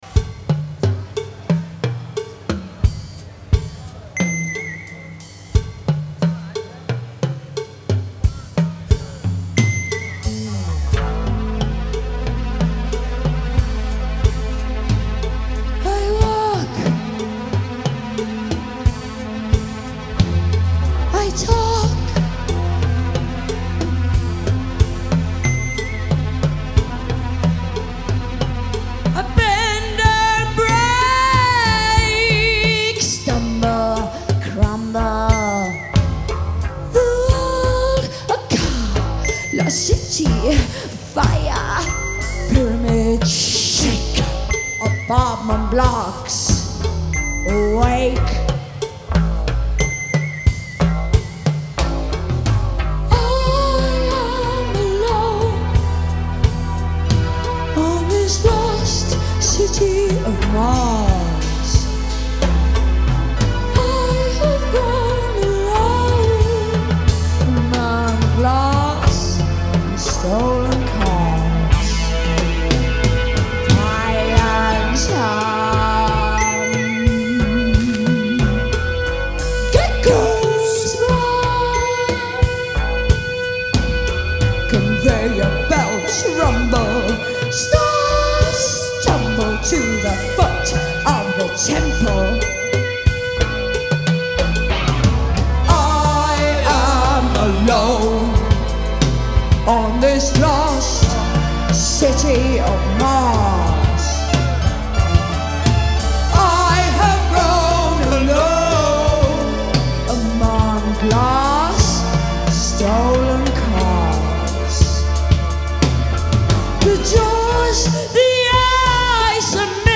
Genre : Rock
Live, Milan